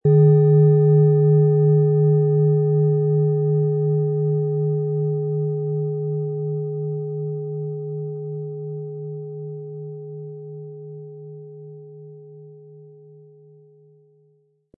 Tibetische Universal-Klangschale, Ø 19,7 cm, 700-800 Gramm, mit Klöppel
Sie sehen eine nach überlieferter Weise von Hand gearbeitete tibetische Klangschale.
Wir haben ebendiese Klangschale beim Aufnehmen angespielt und das persönliche Empfinden, dass sie alle Körperregionen gleich deutlich zum Schwingen bringt.
Unter dem Artikel-Bild finden Sie den Original-Klang dieser Schale im Audio-Player - Jetzt reinhören.
Lieferung mit richtigem Schlägel, er lässt die Planetenschale harmonisch und wohltuend schwingen.